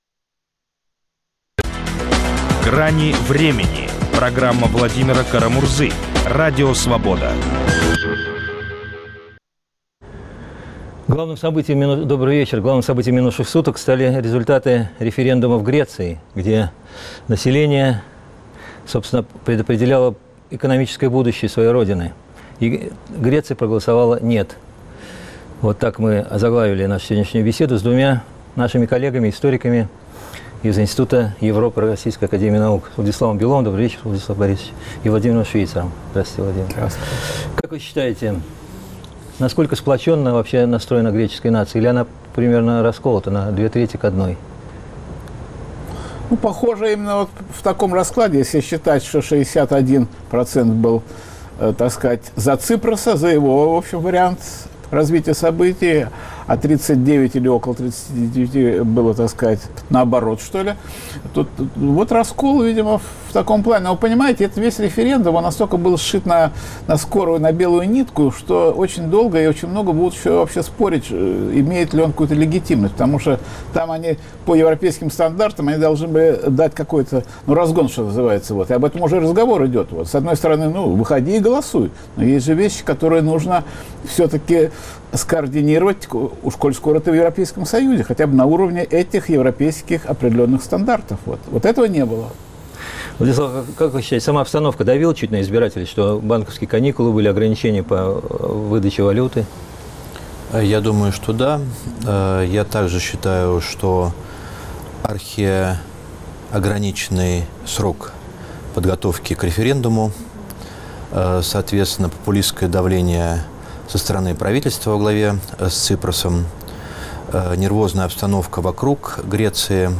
Есть разные точки зрения, многие из них - в "Гранях времени". Ведущий Владимир Кара-Мурза предлагает соглашаться, уточнять, дополнять и спорить, сделать программу дискуссионным клубом.